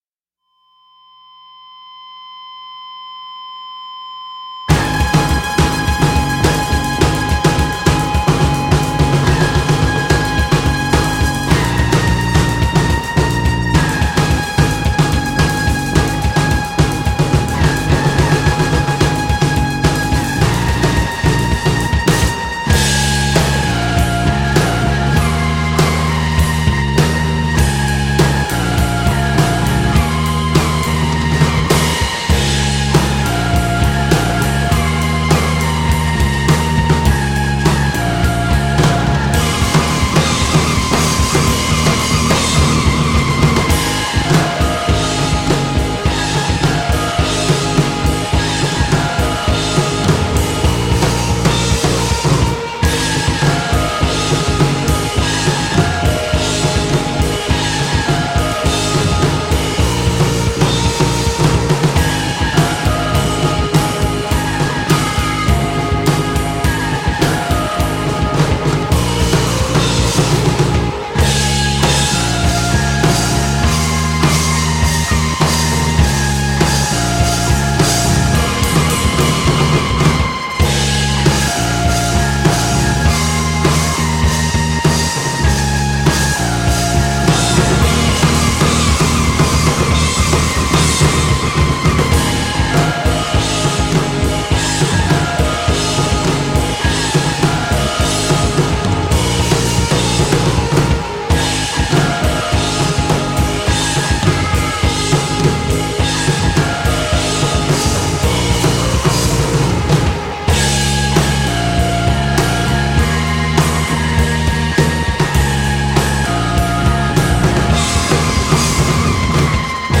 Drums & Percussion